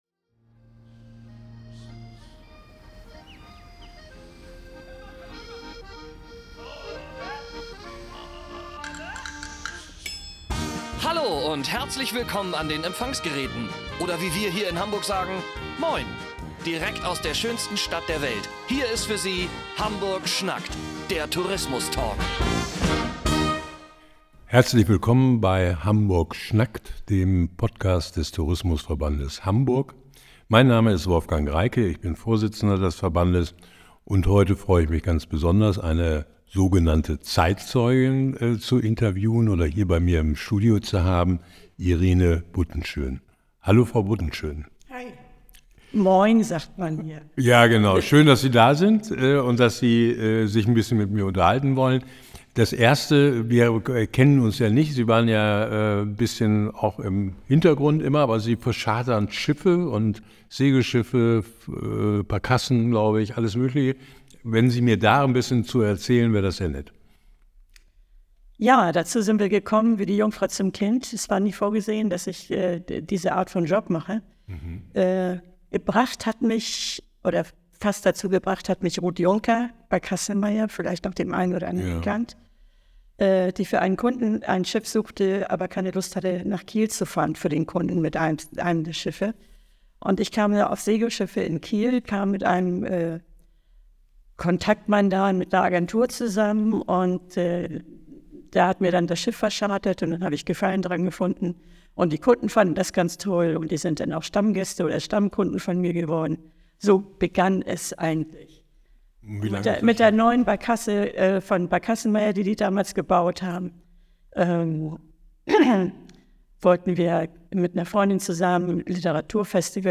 Hamburg schnackt – Der Tourismus Talk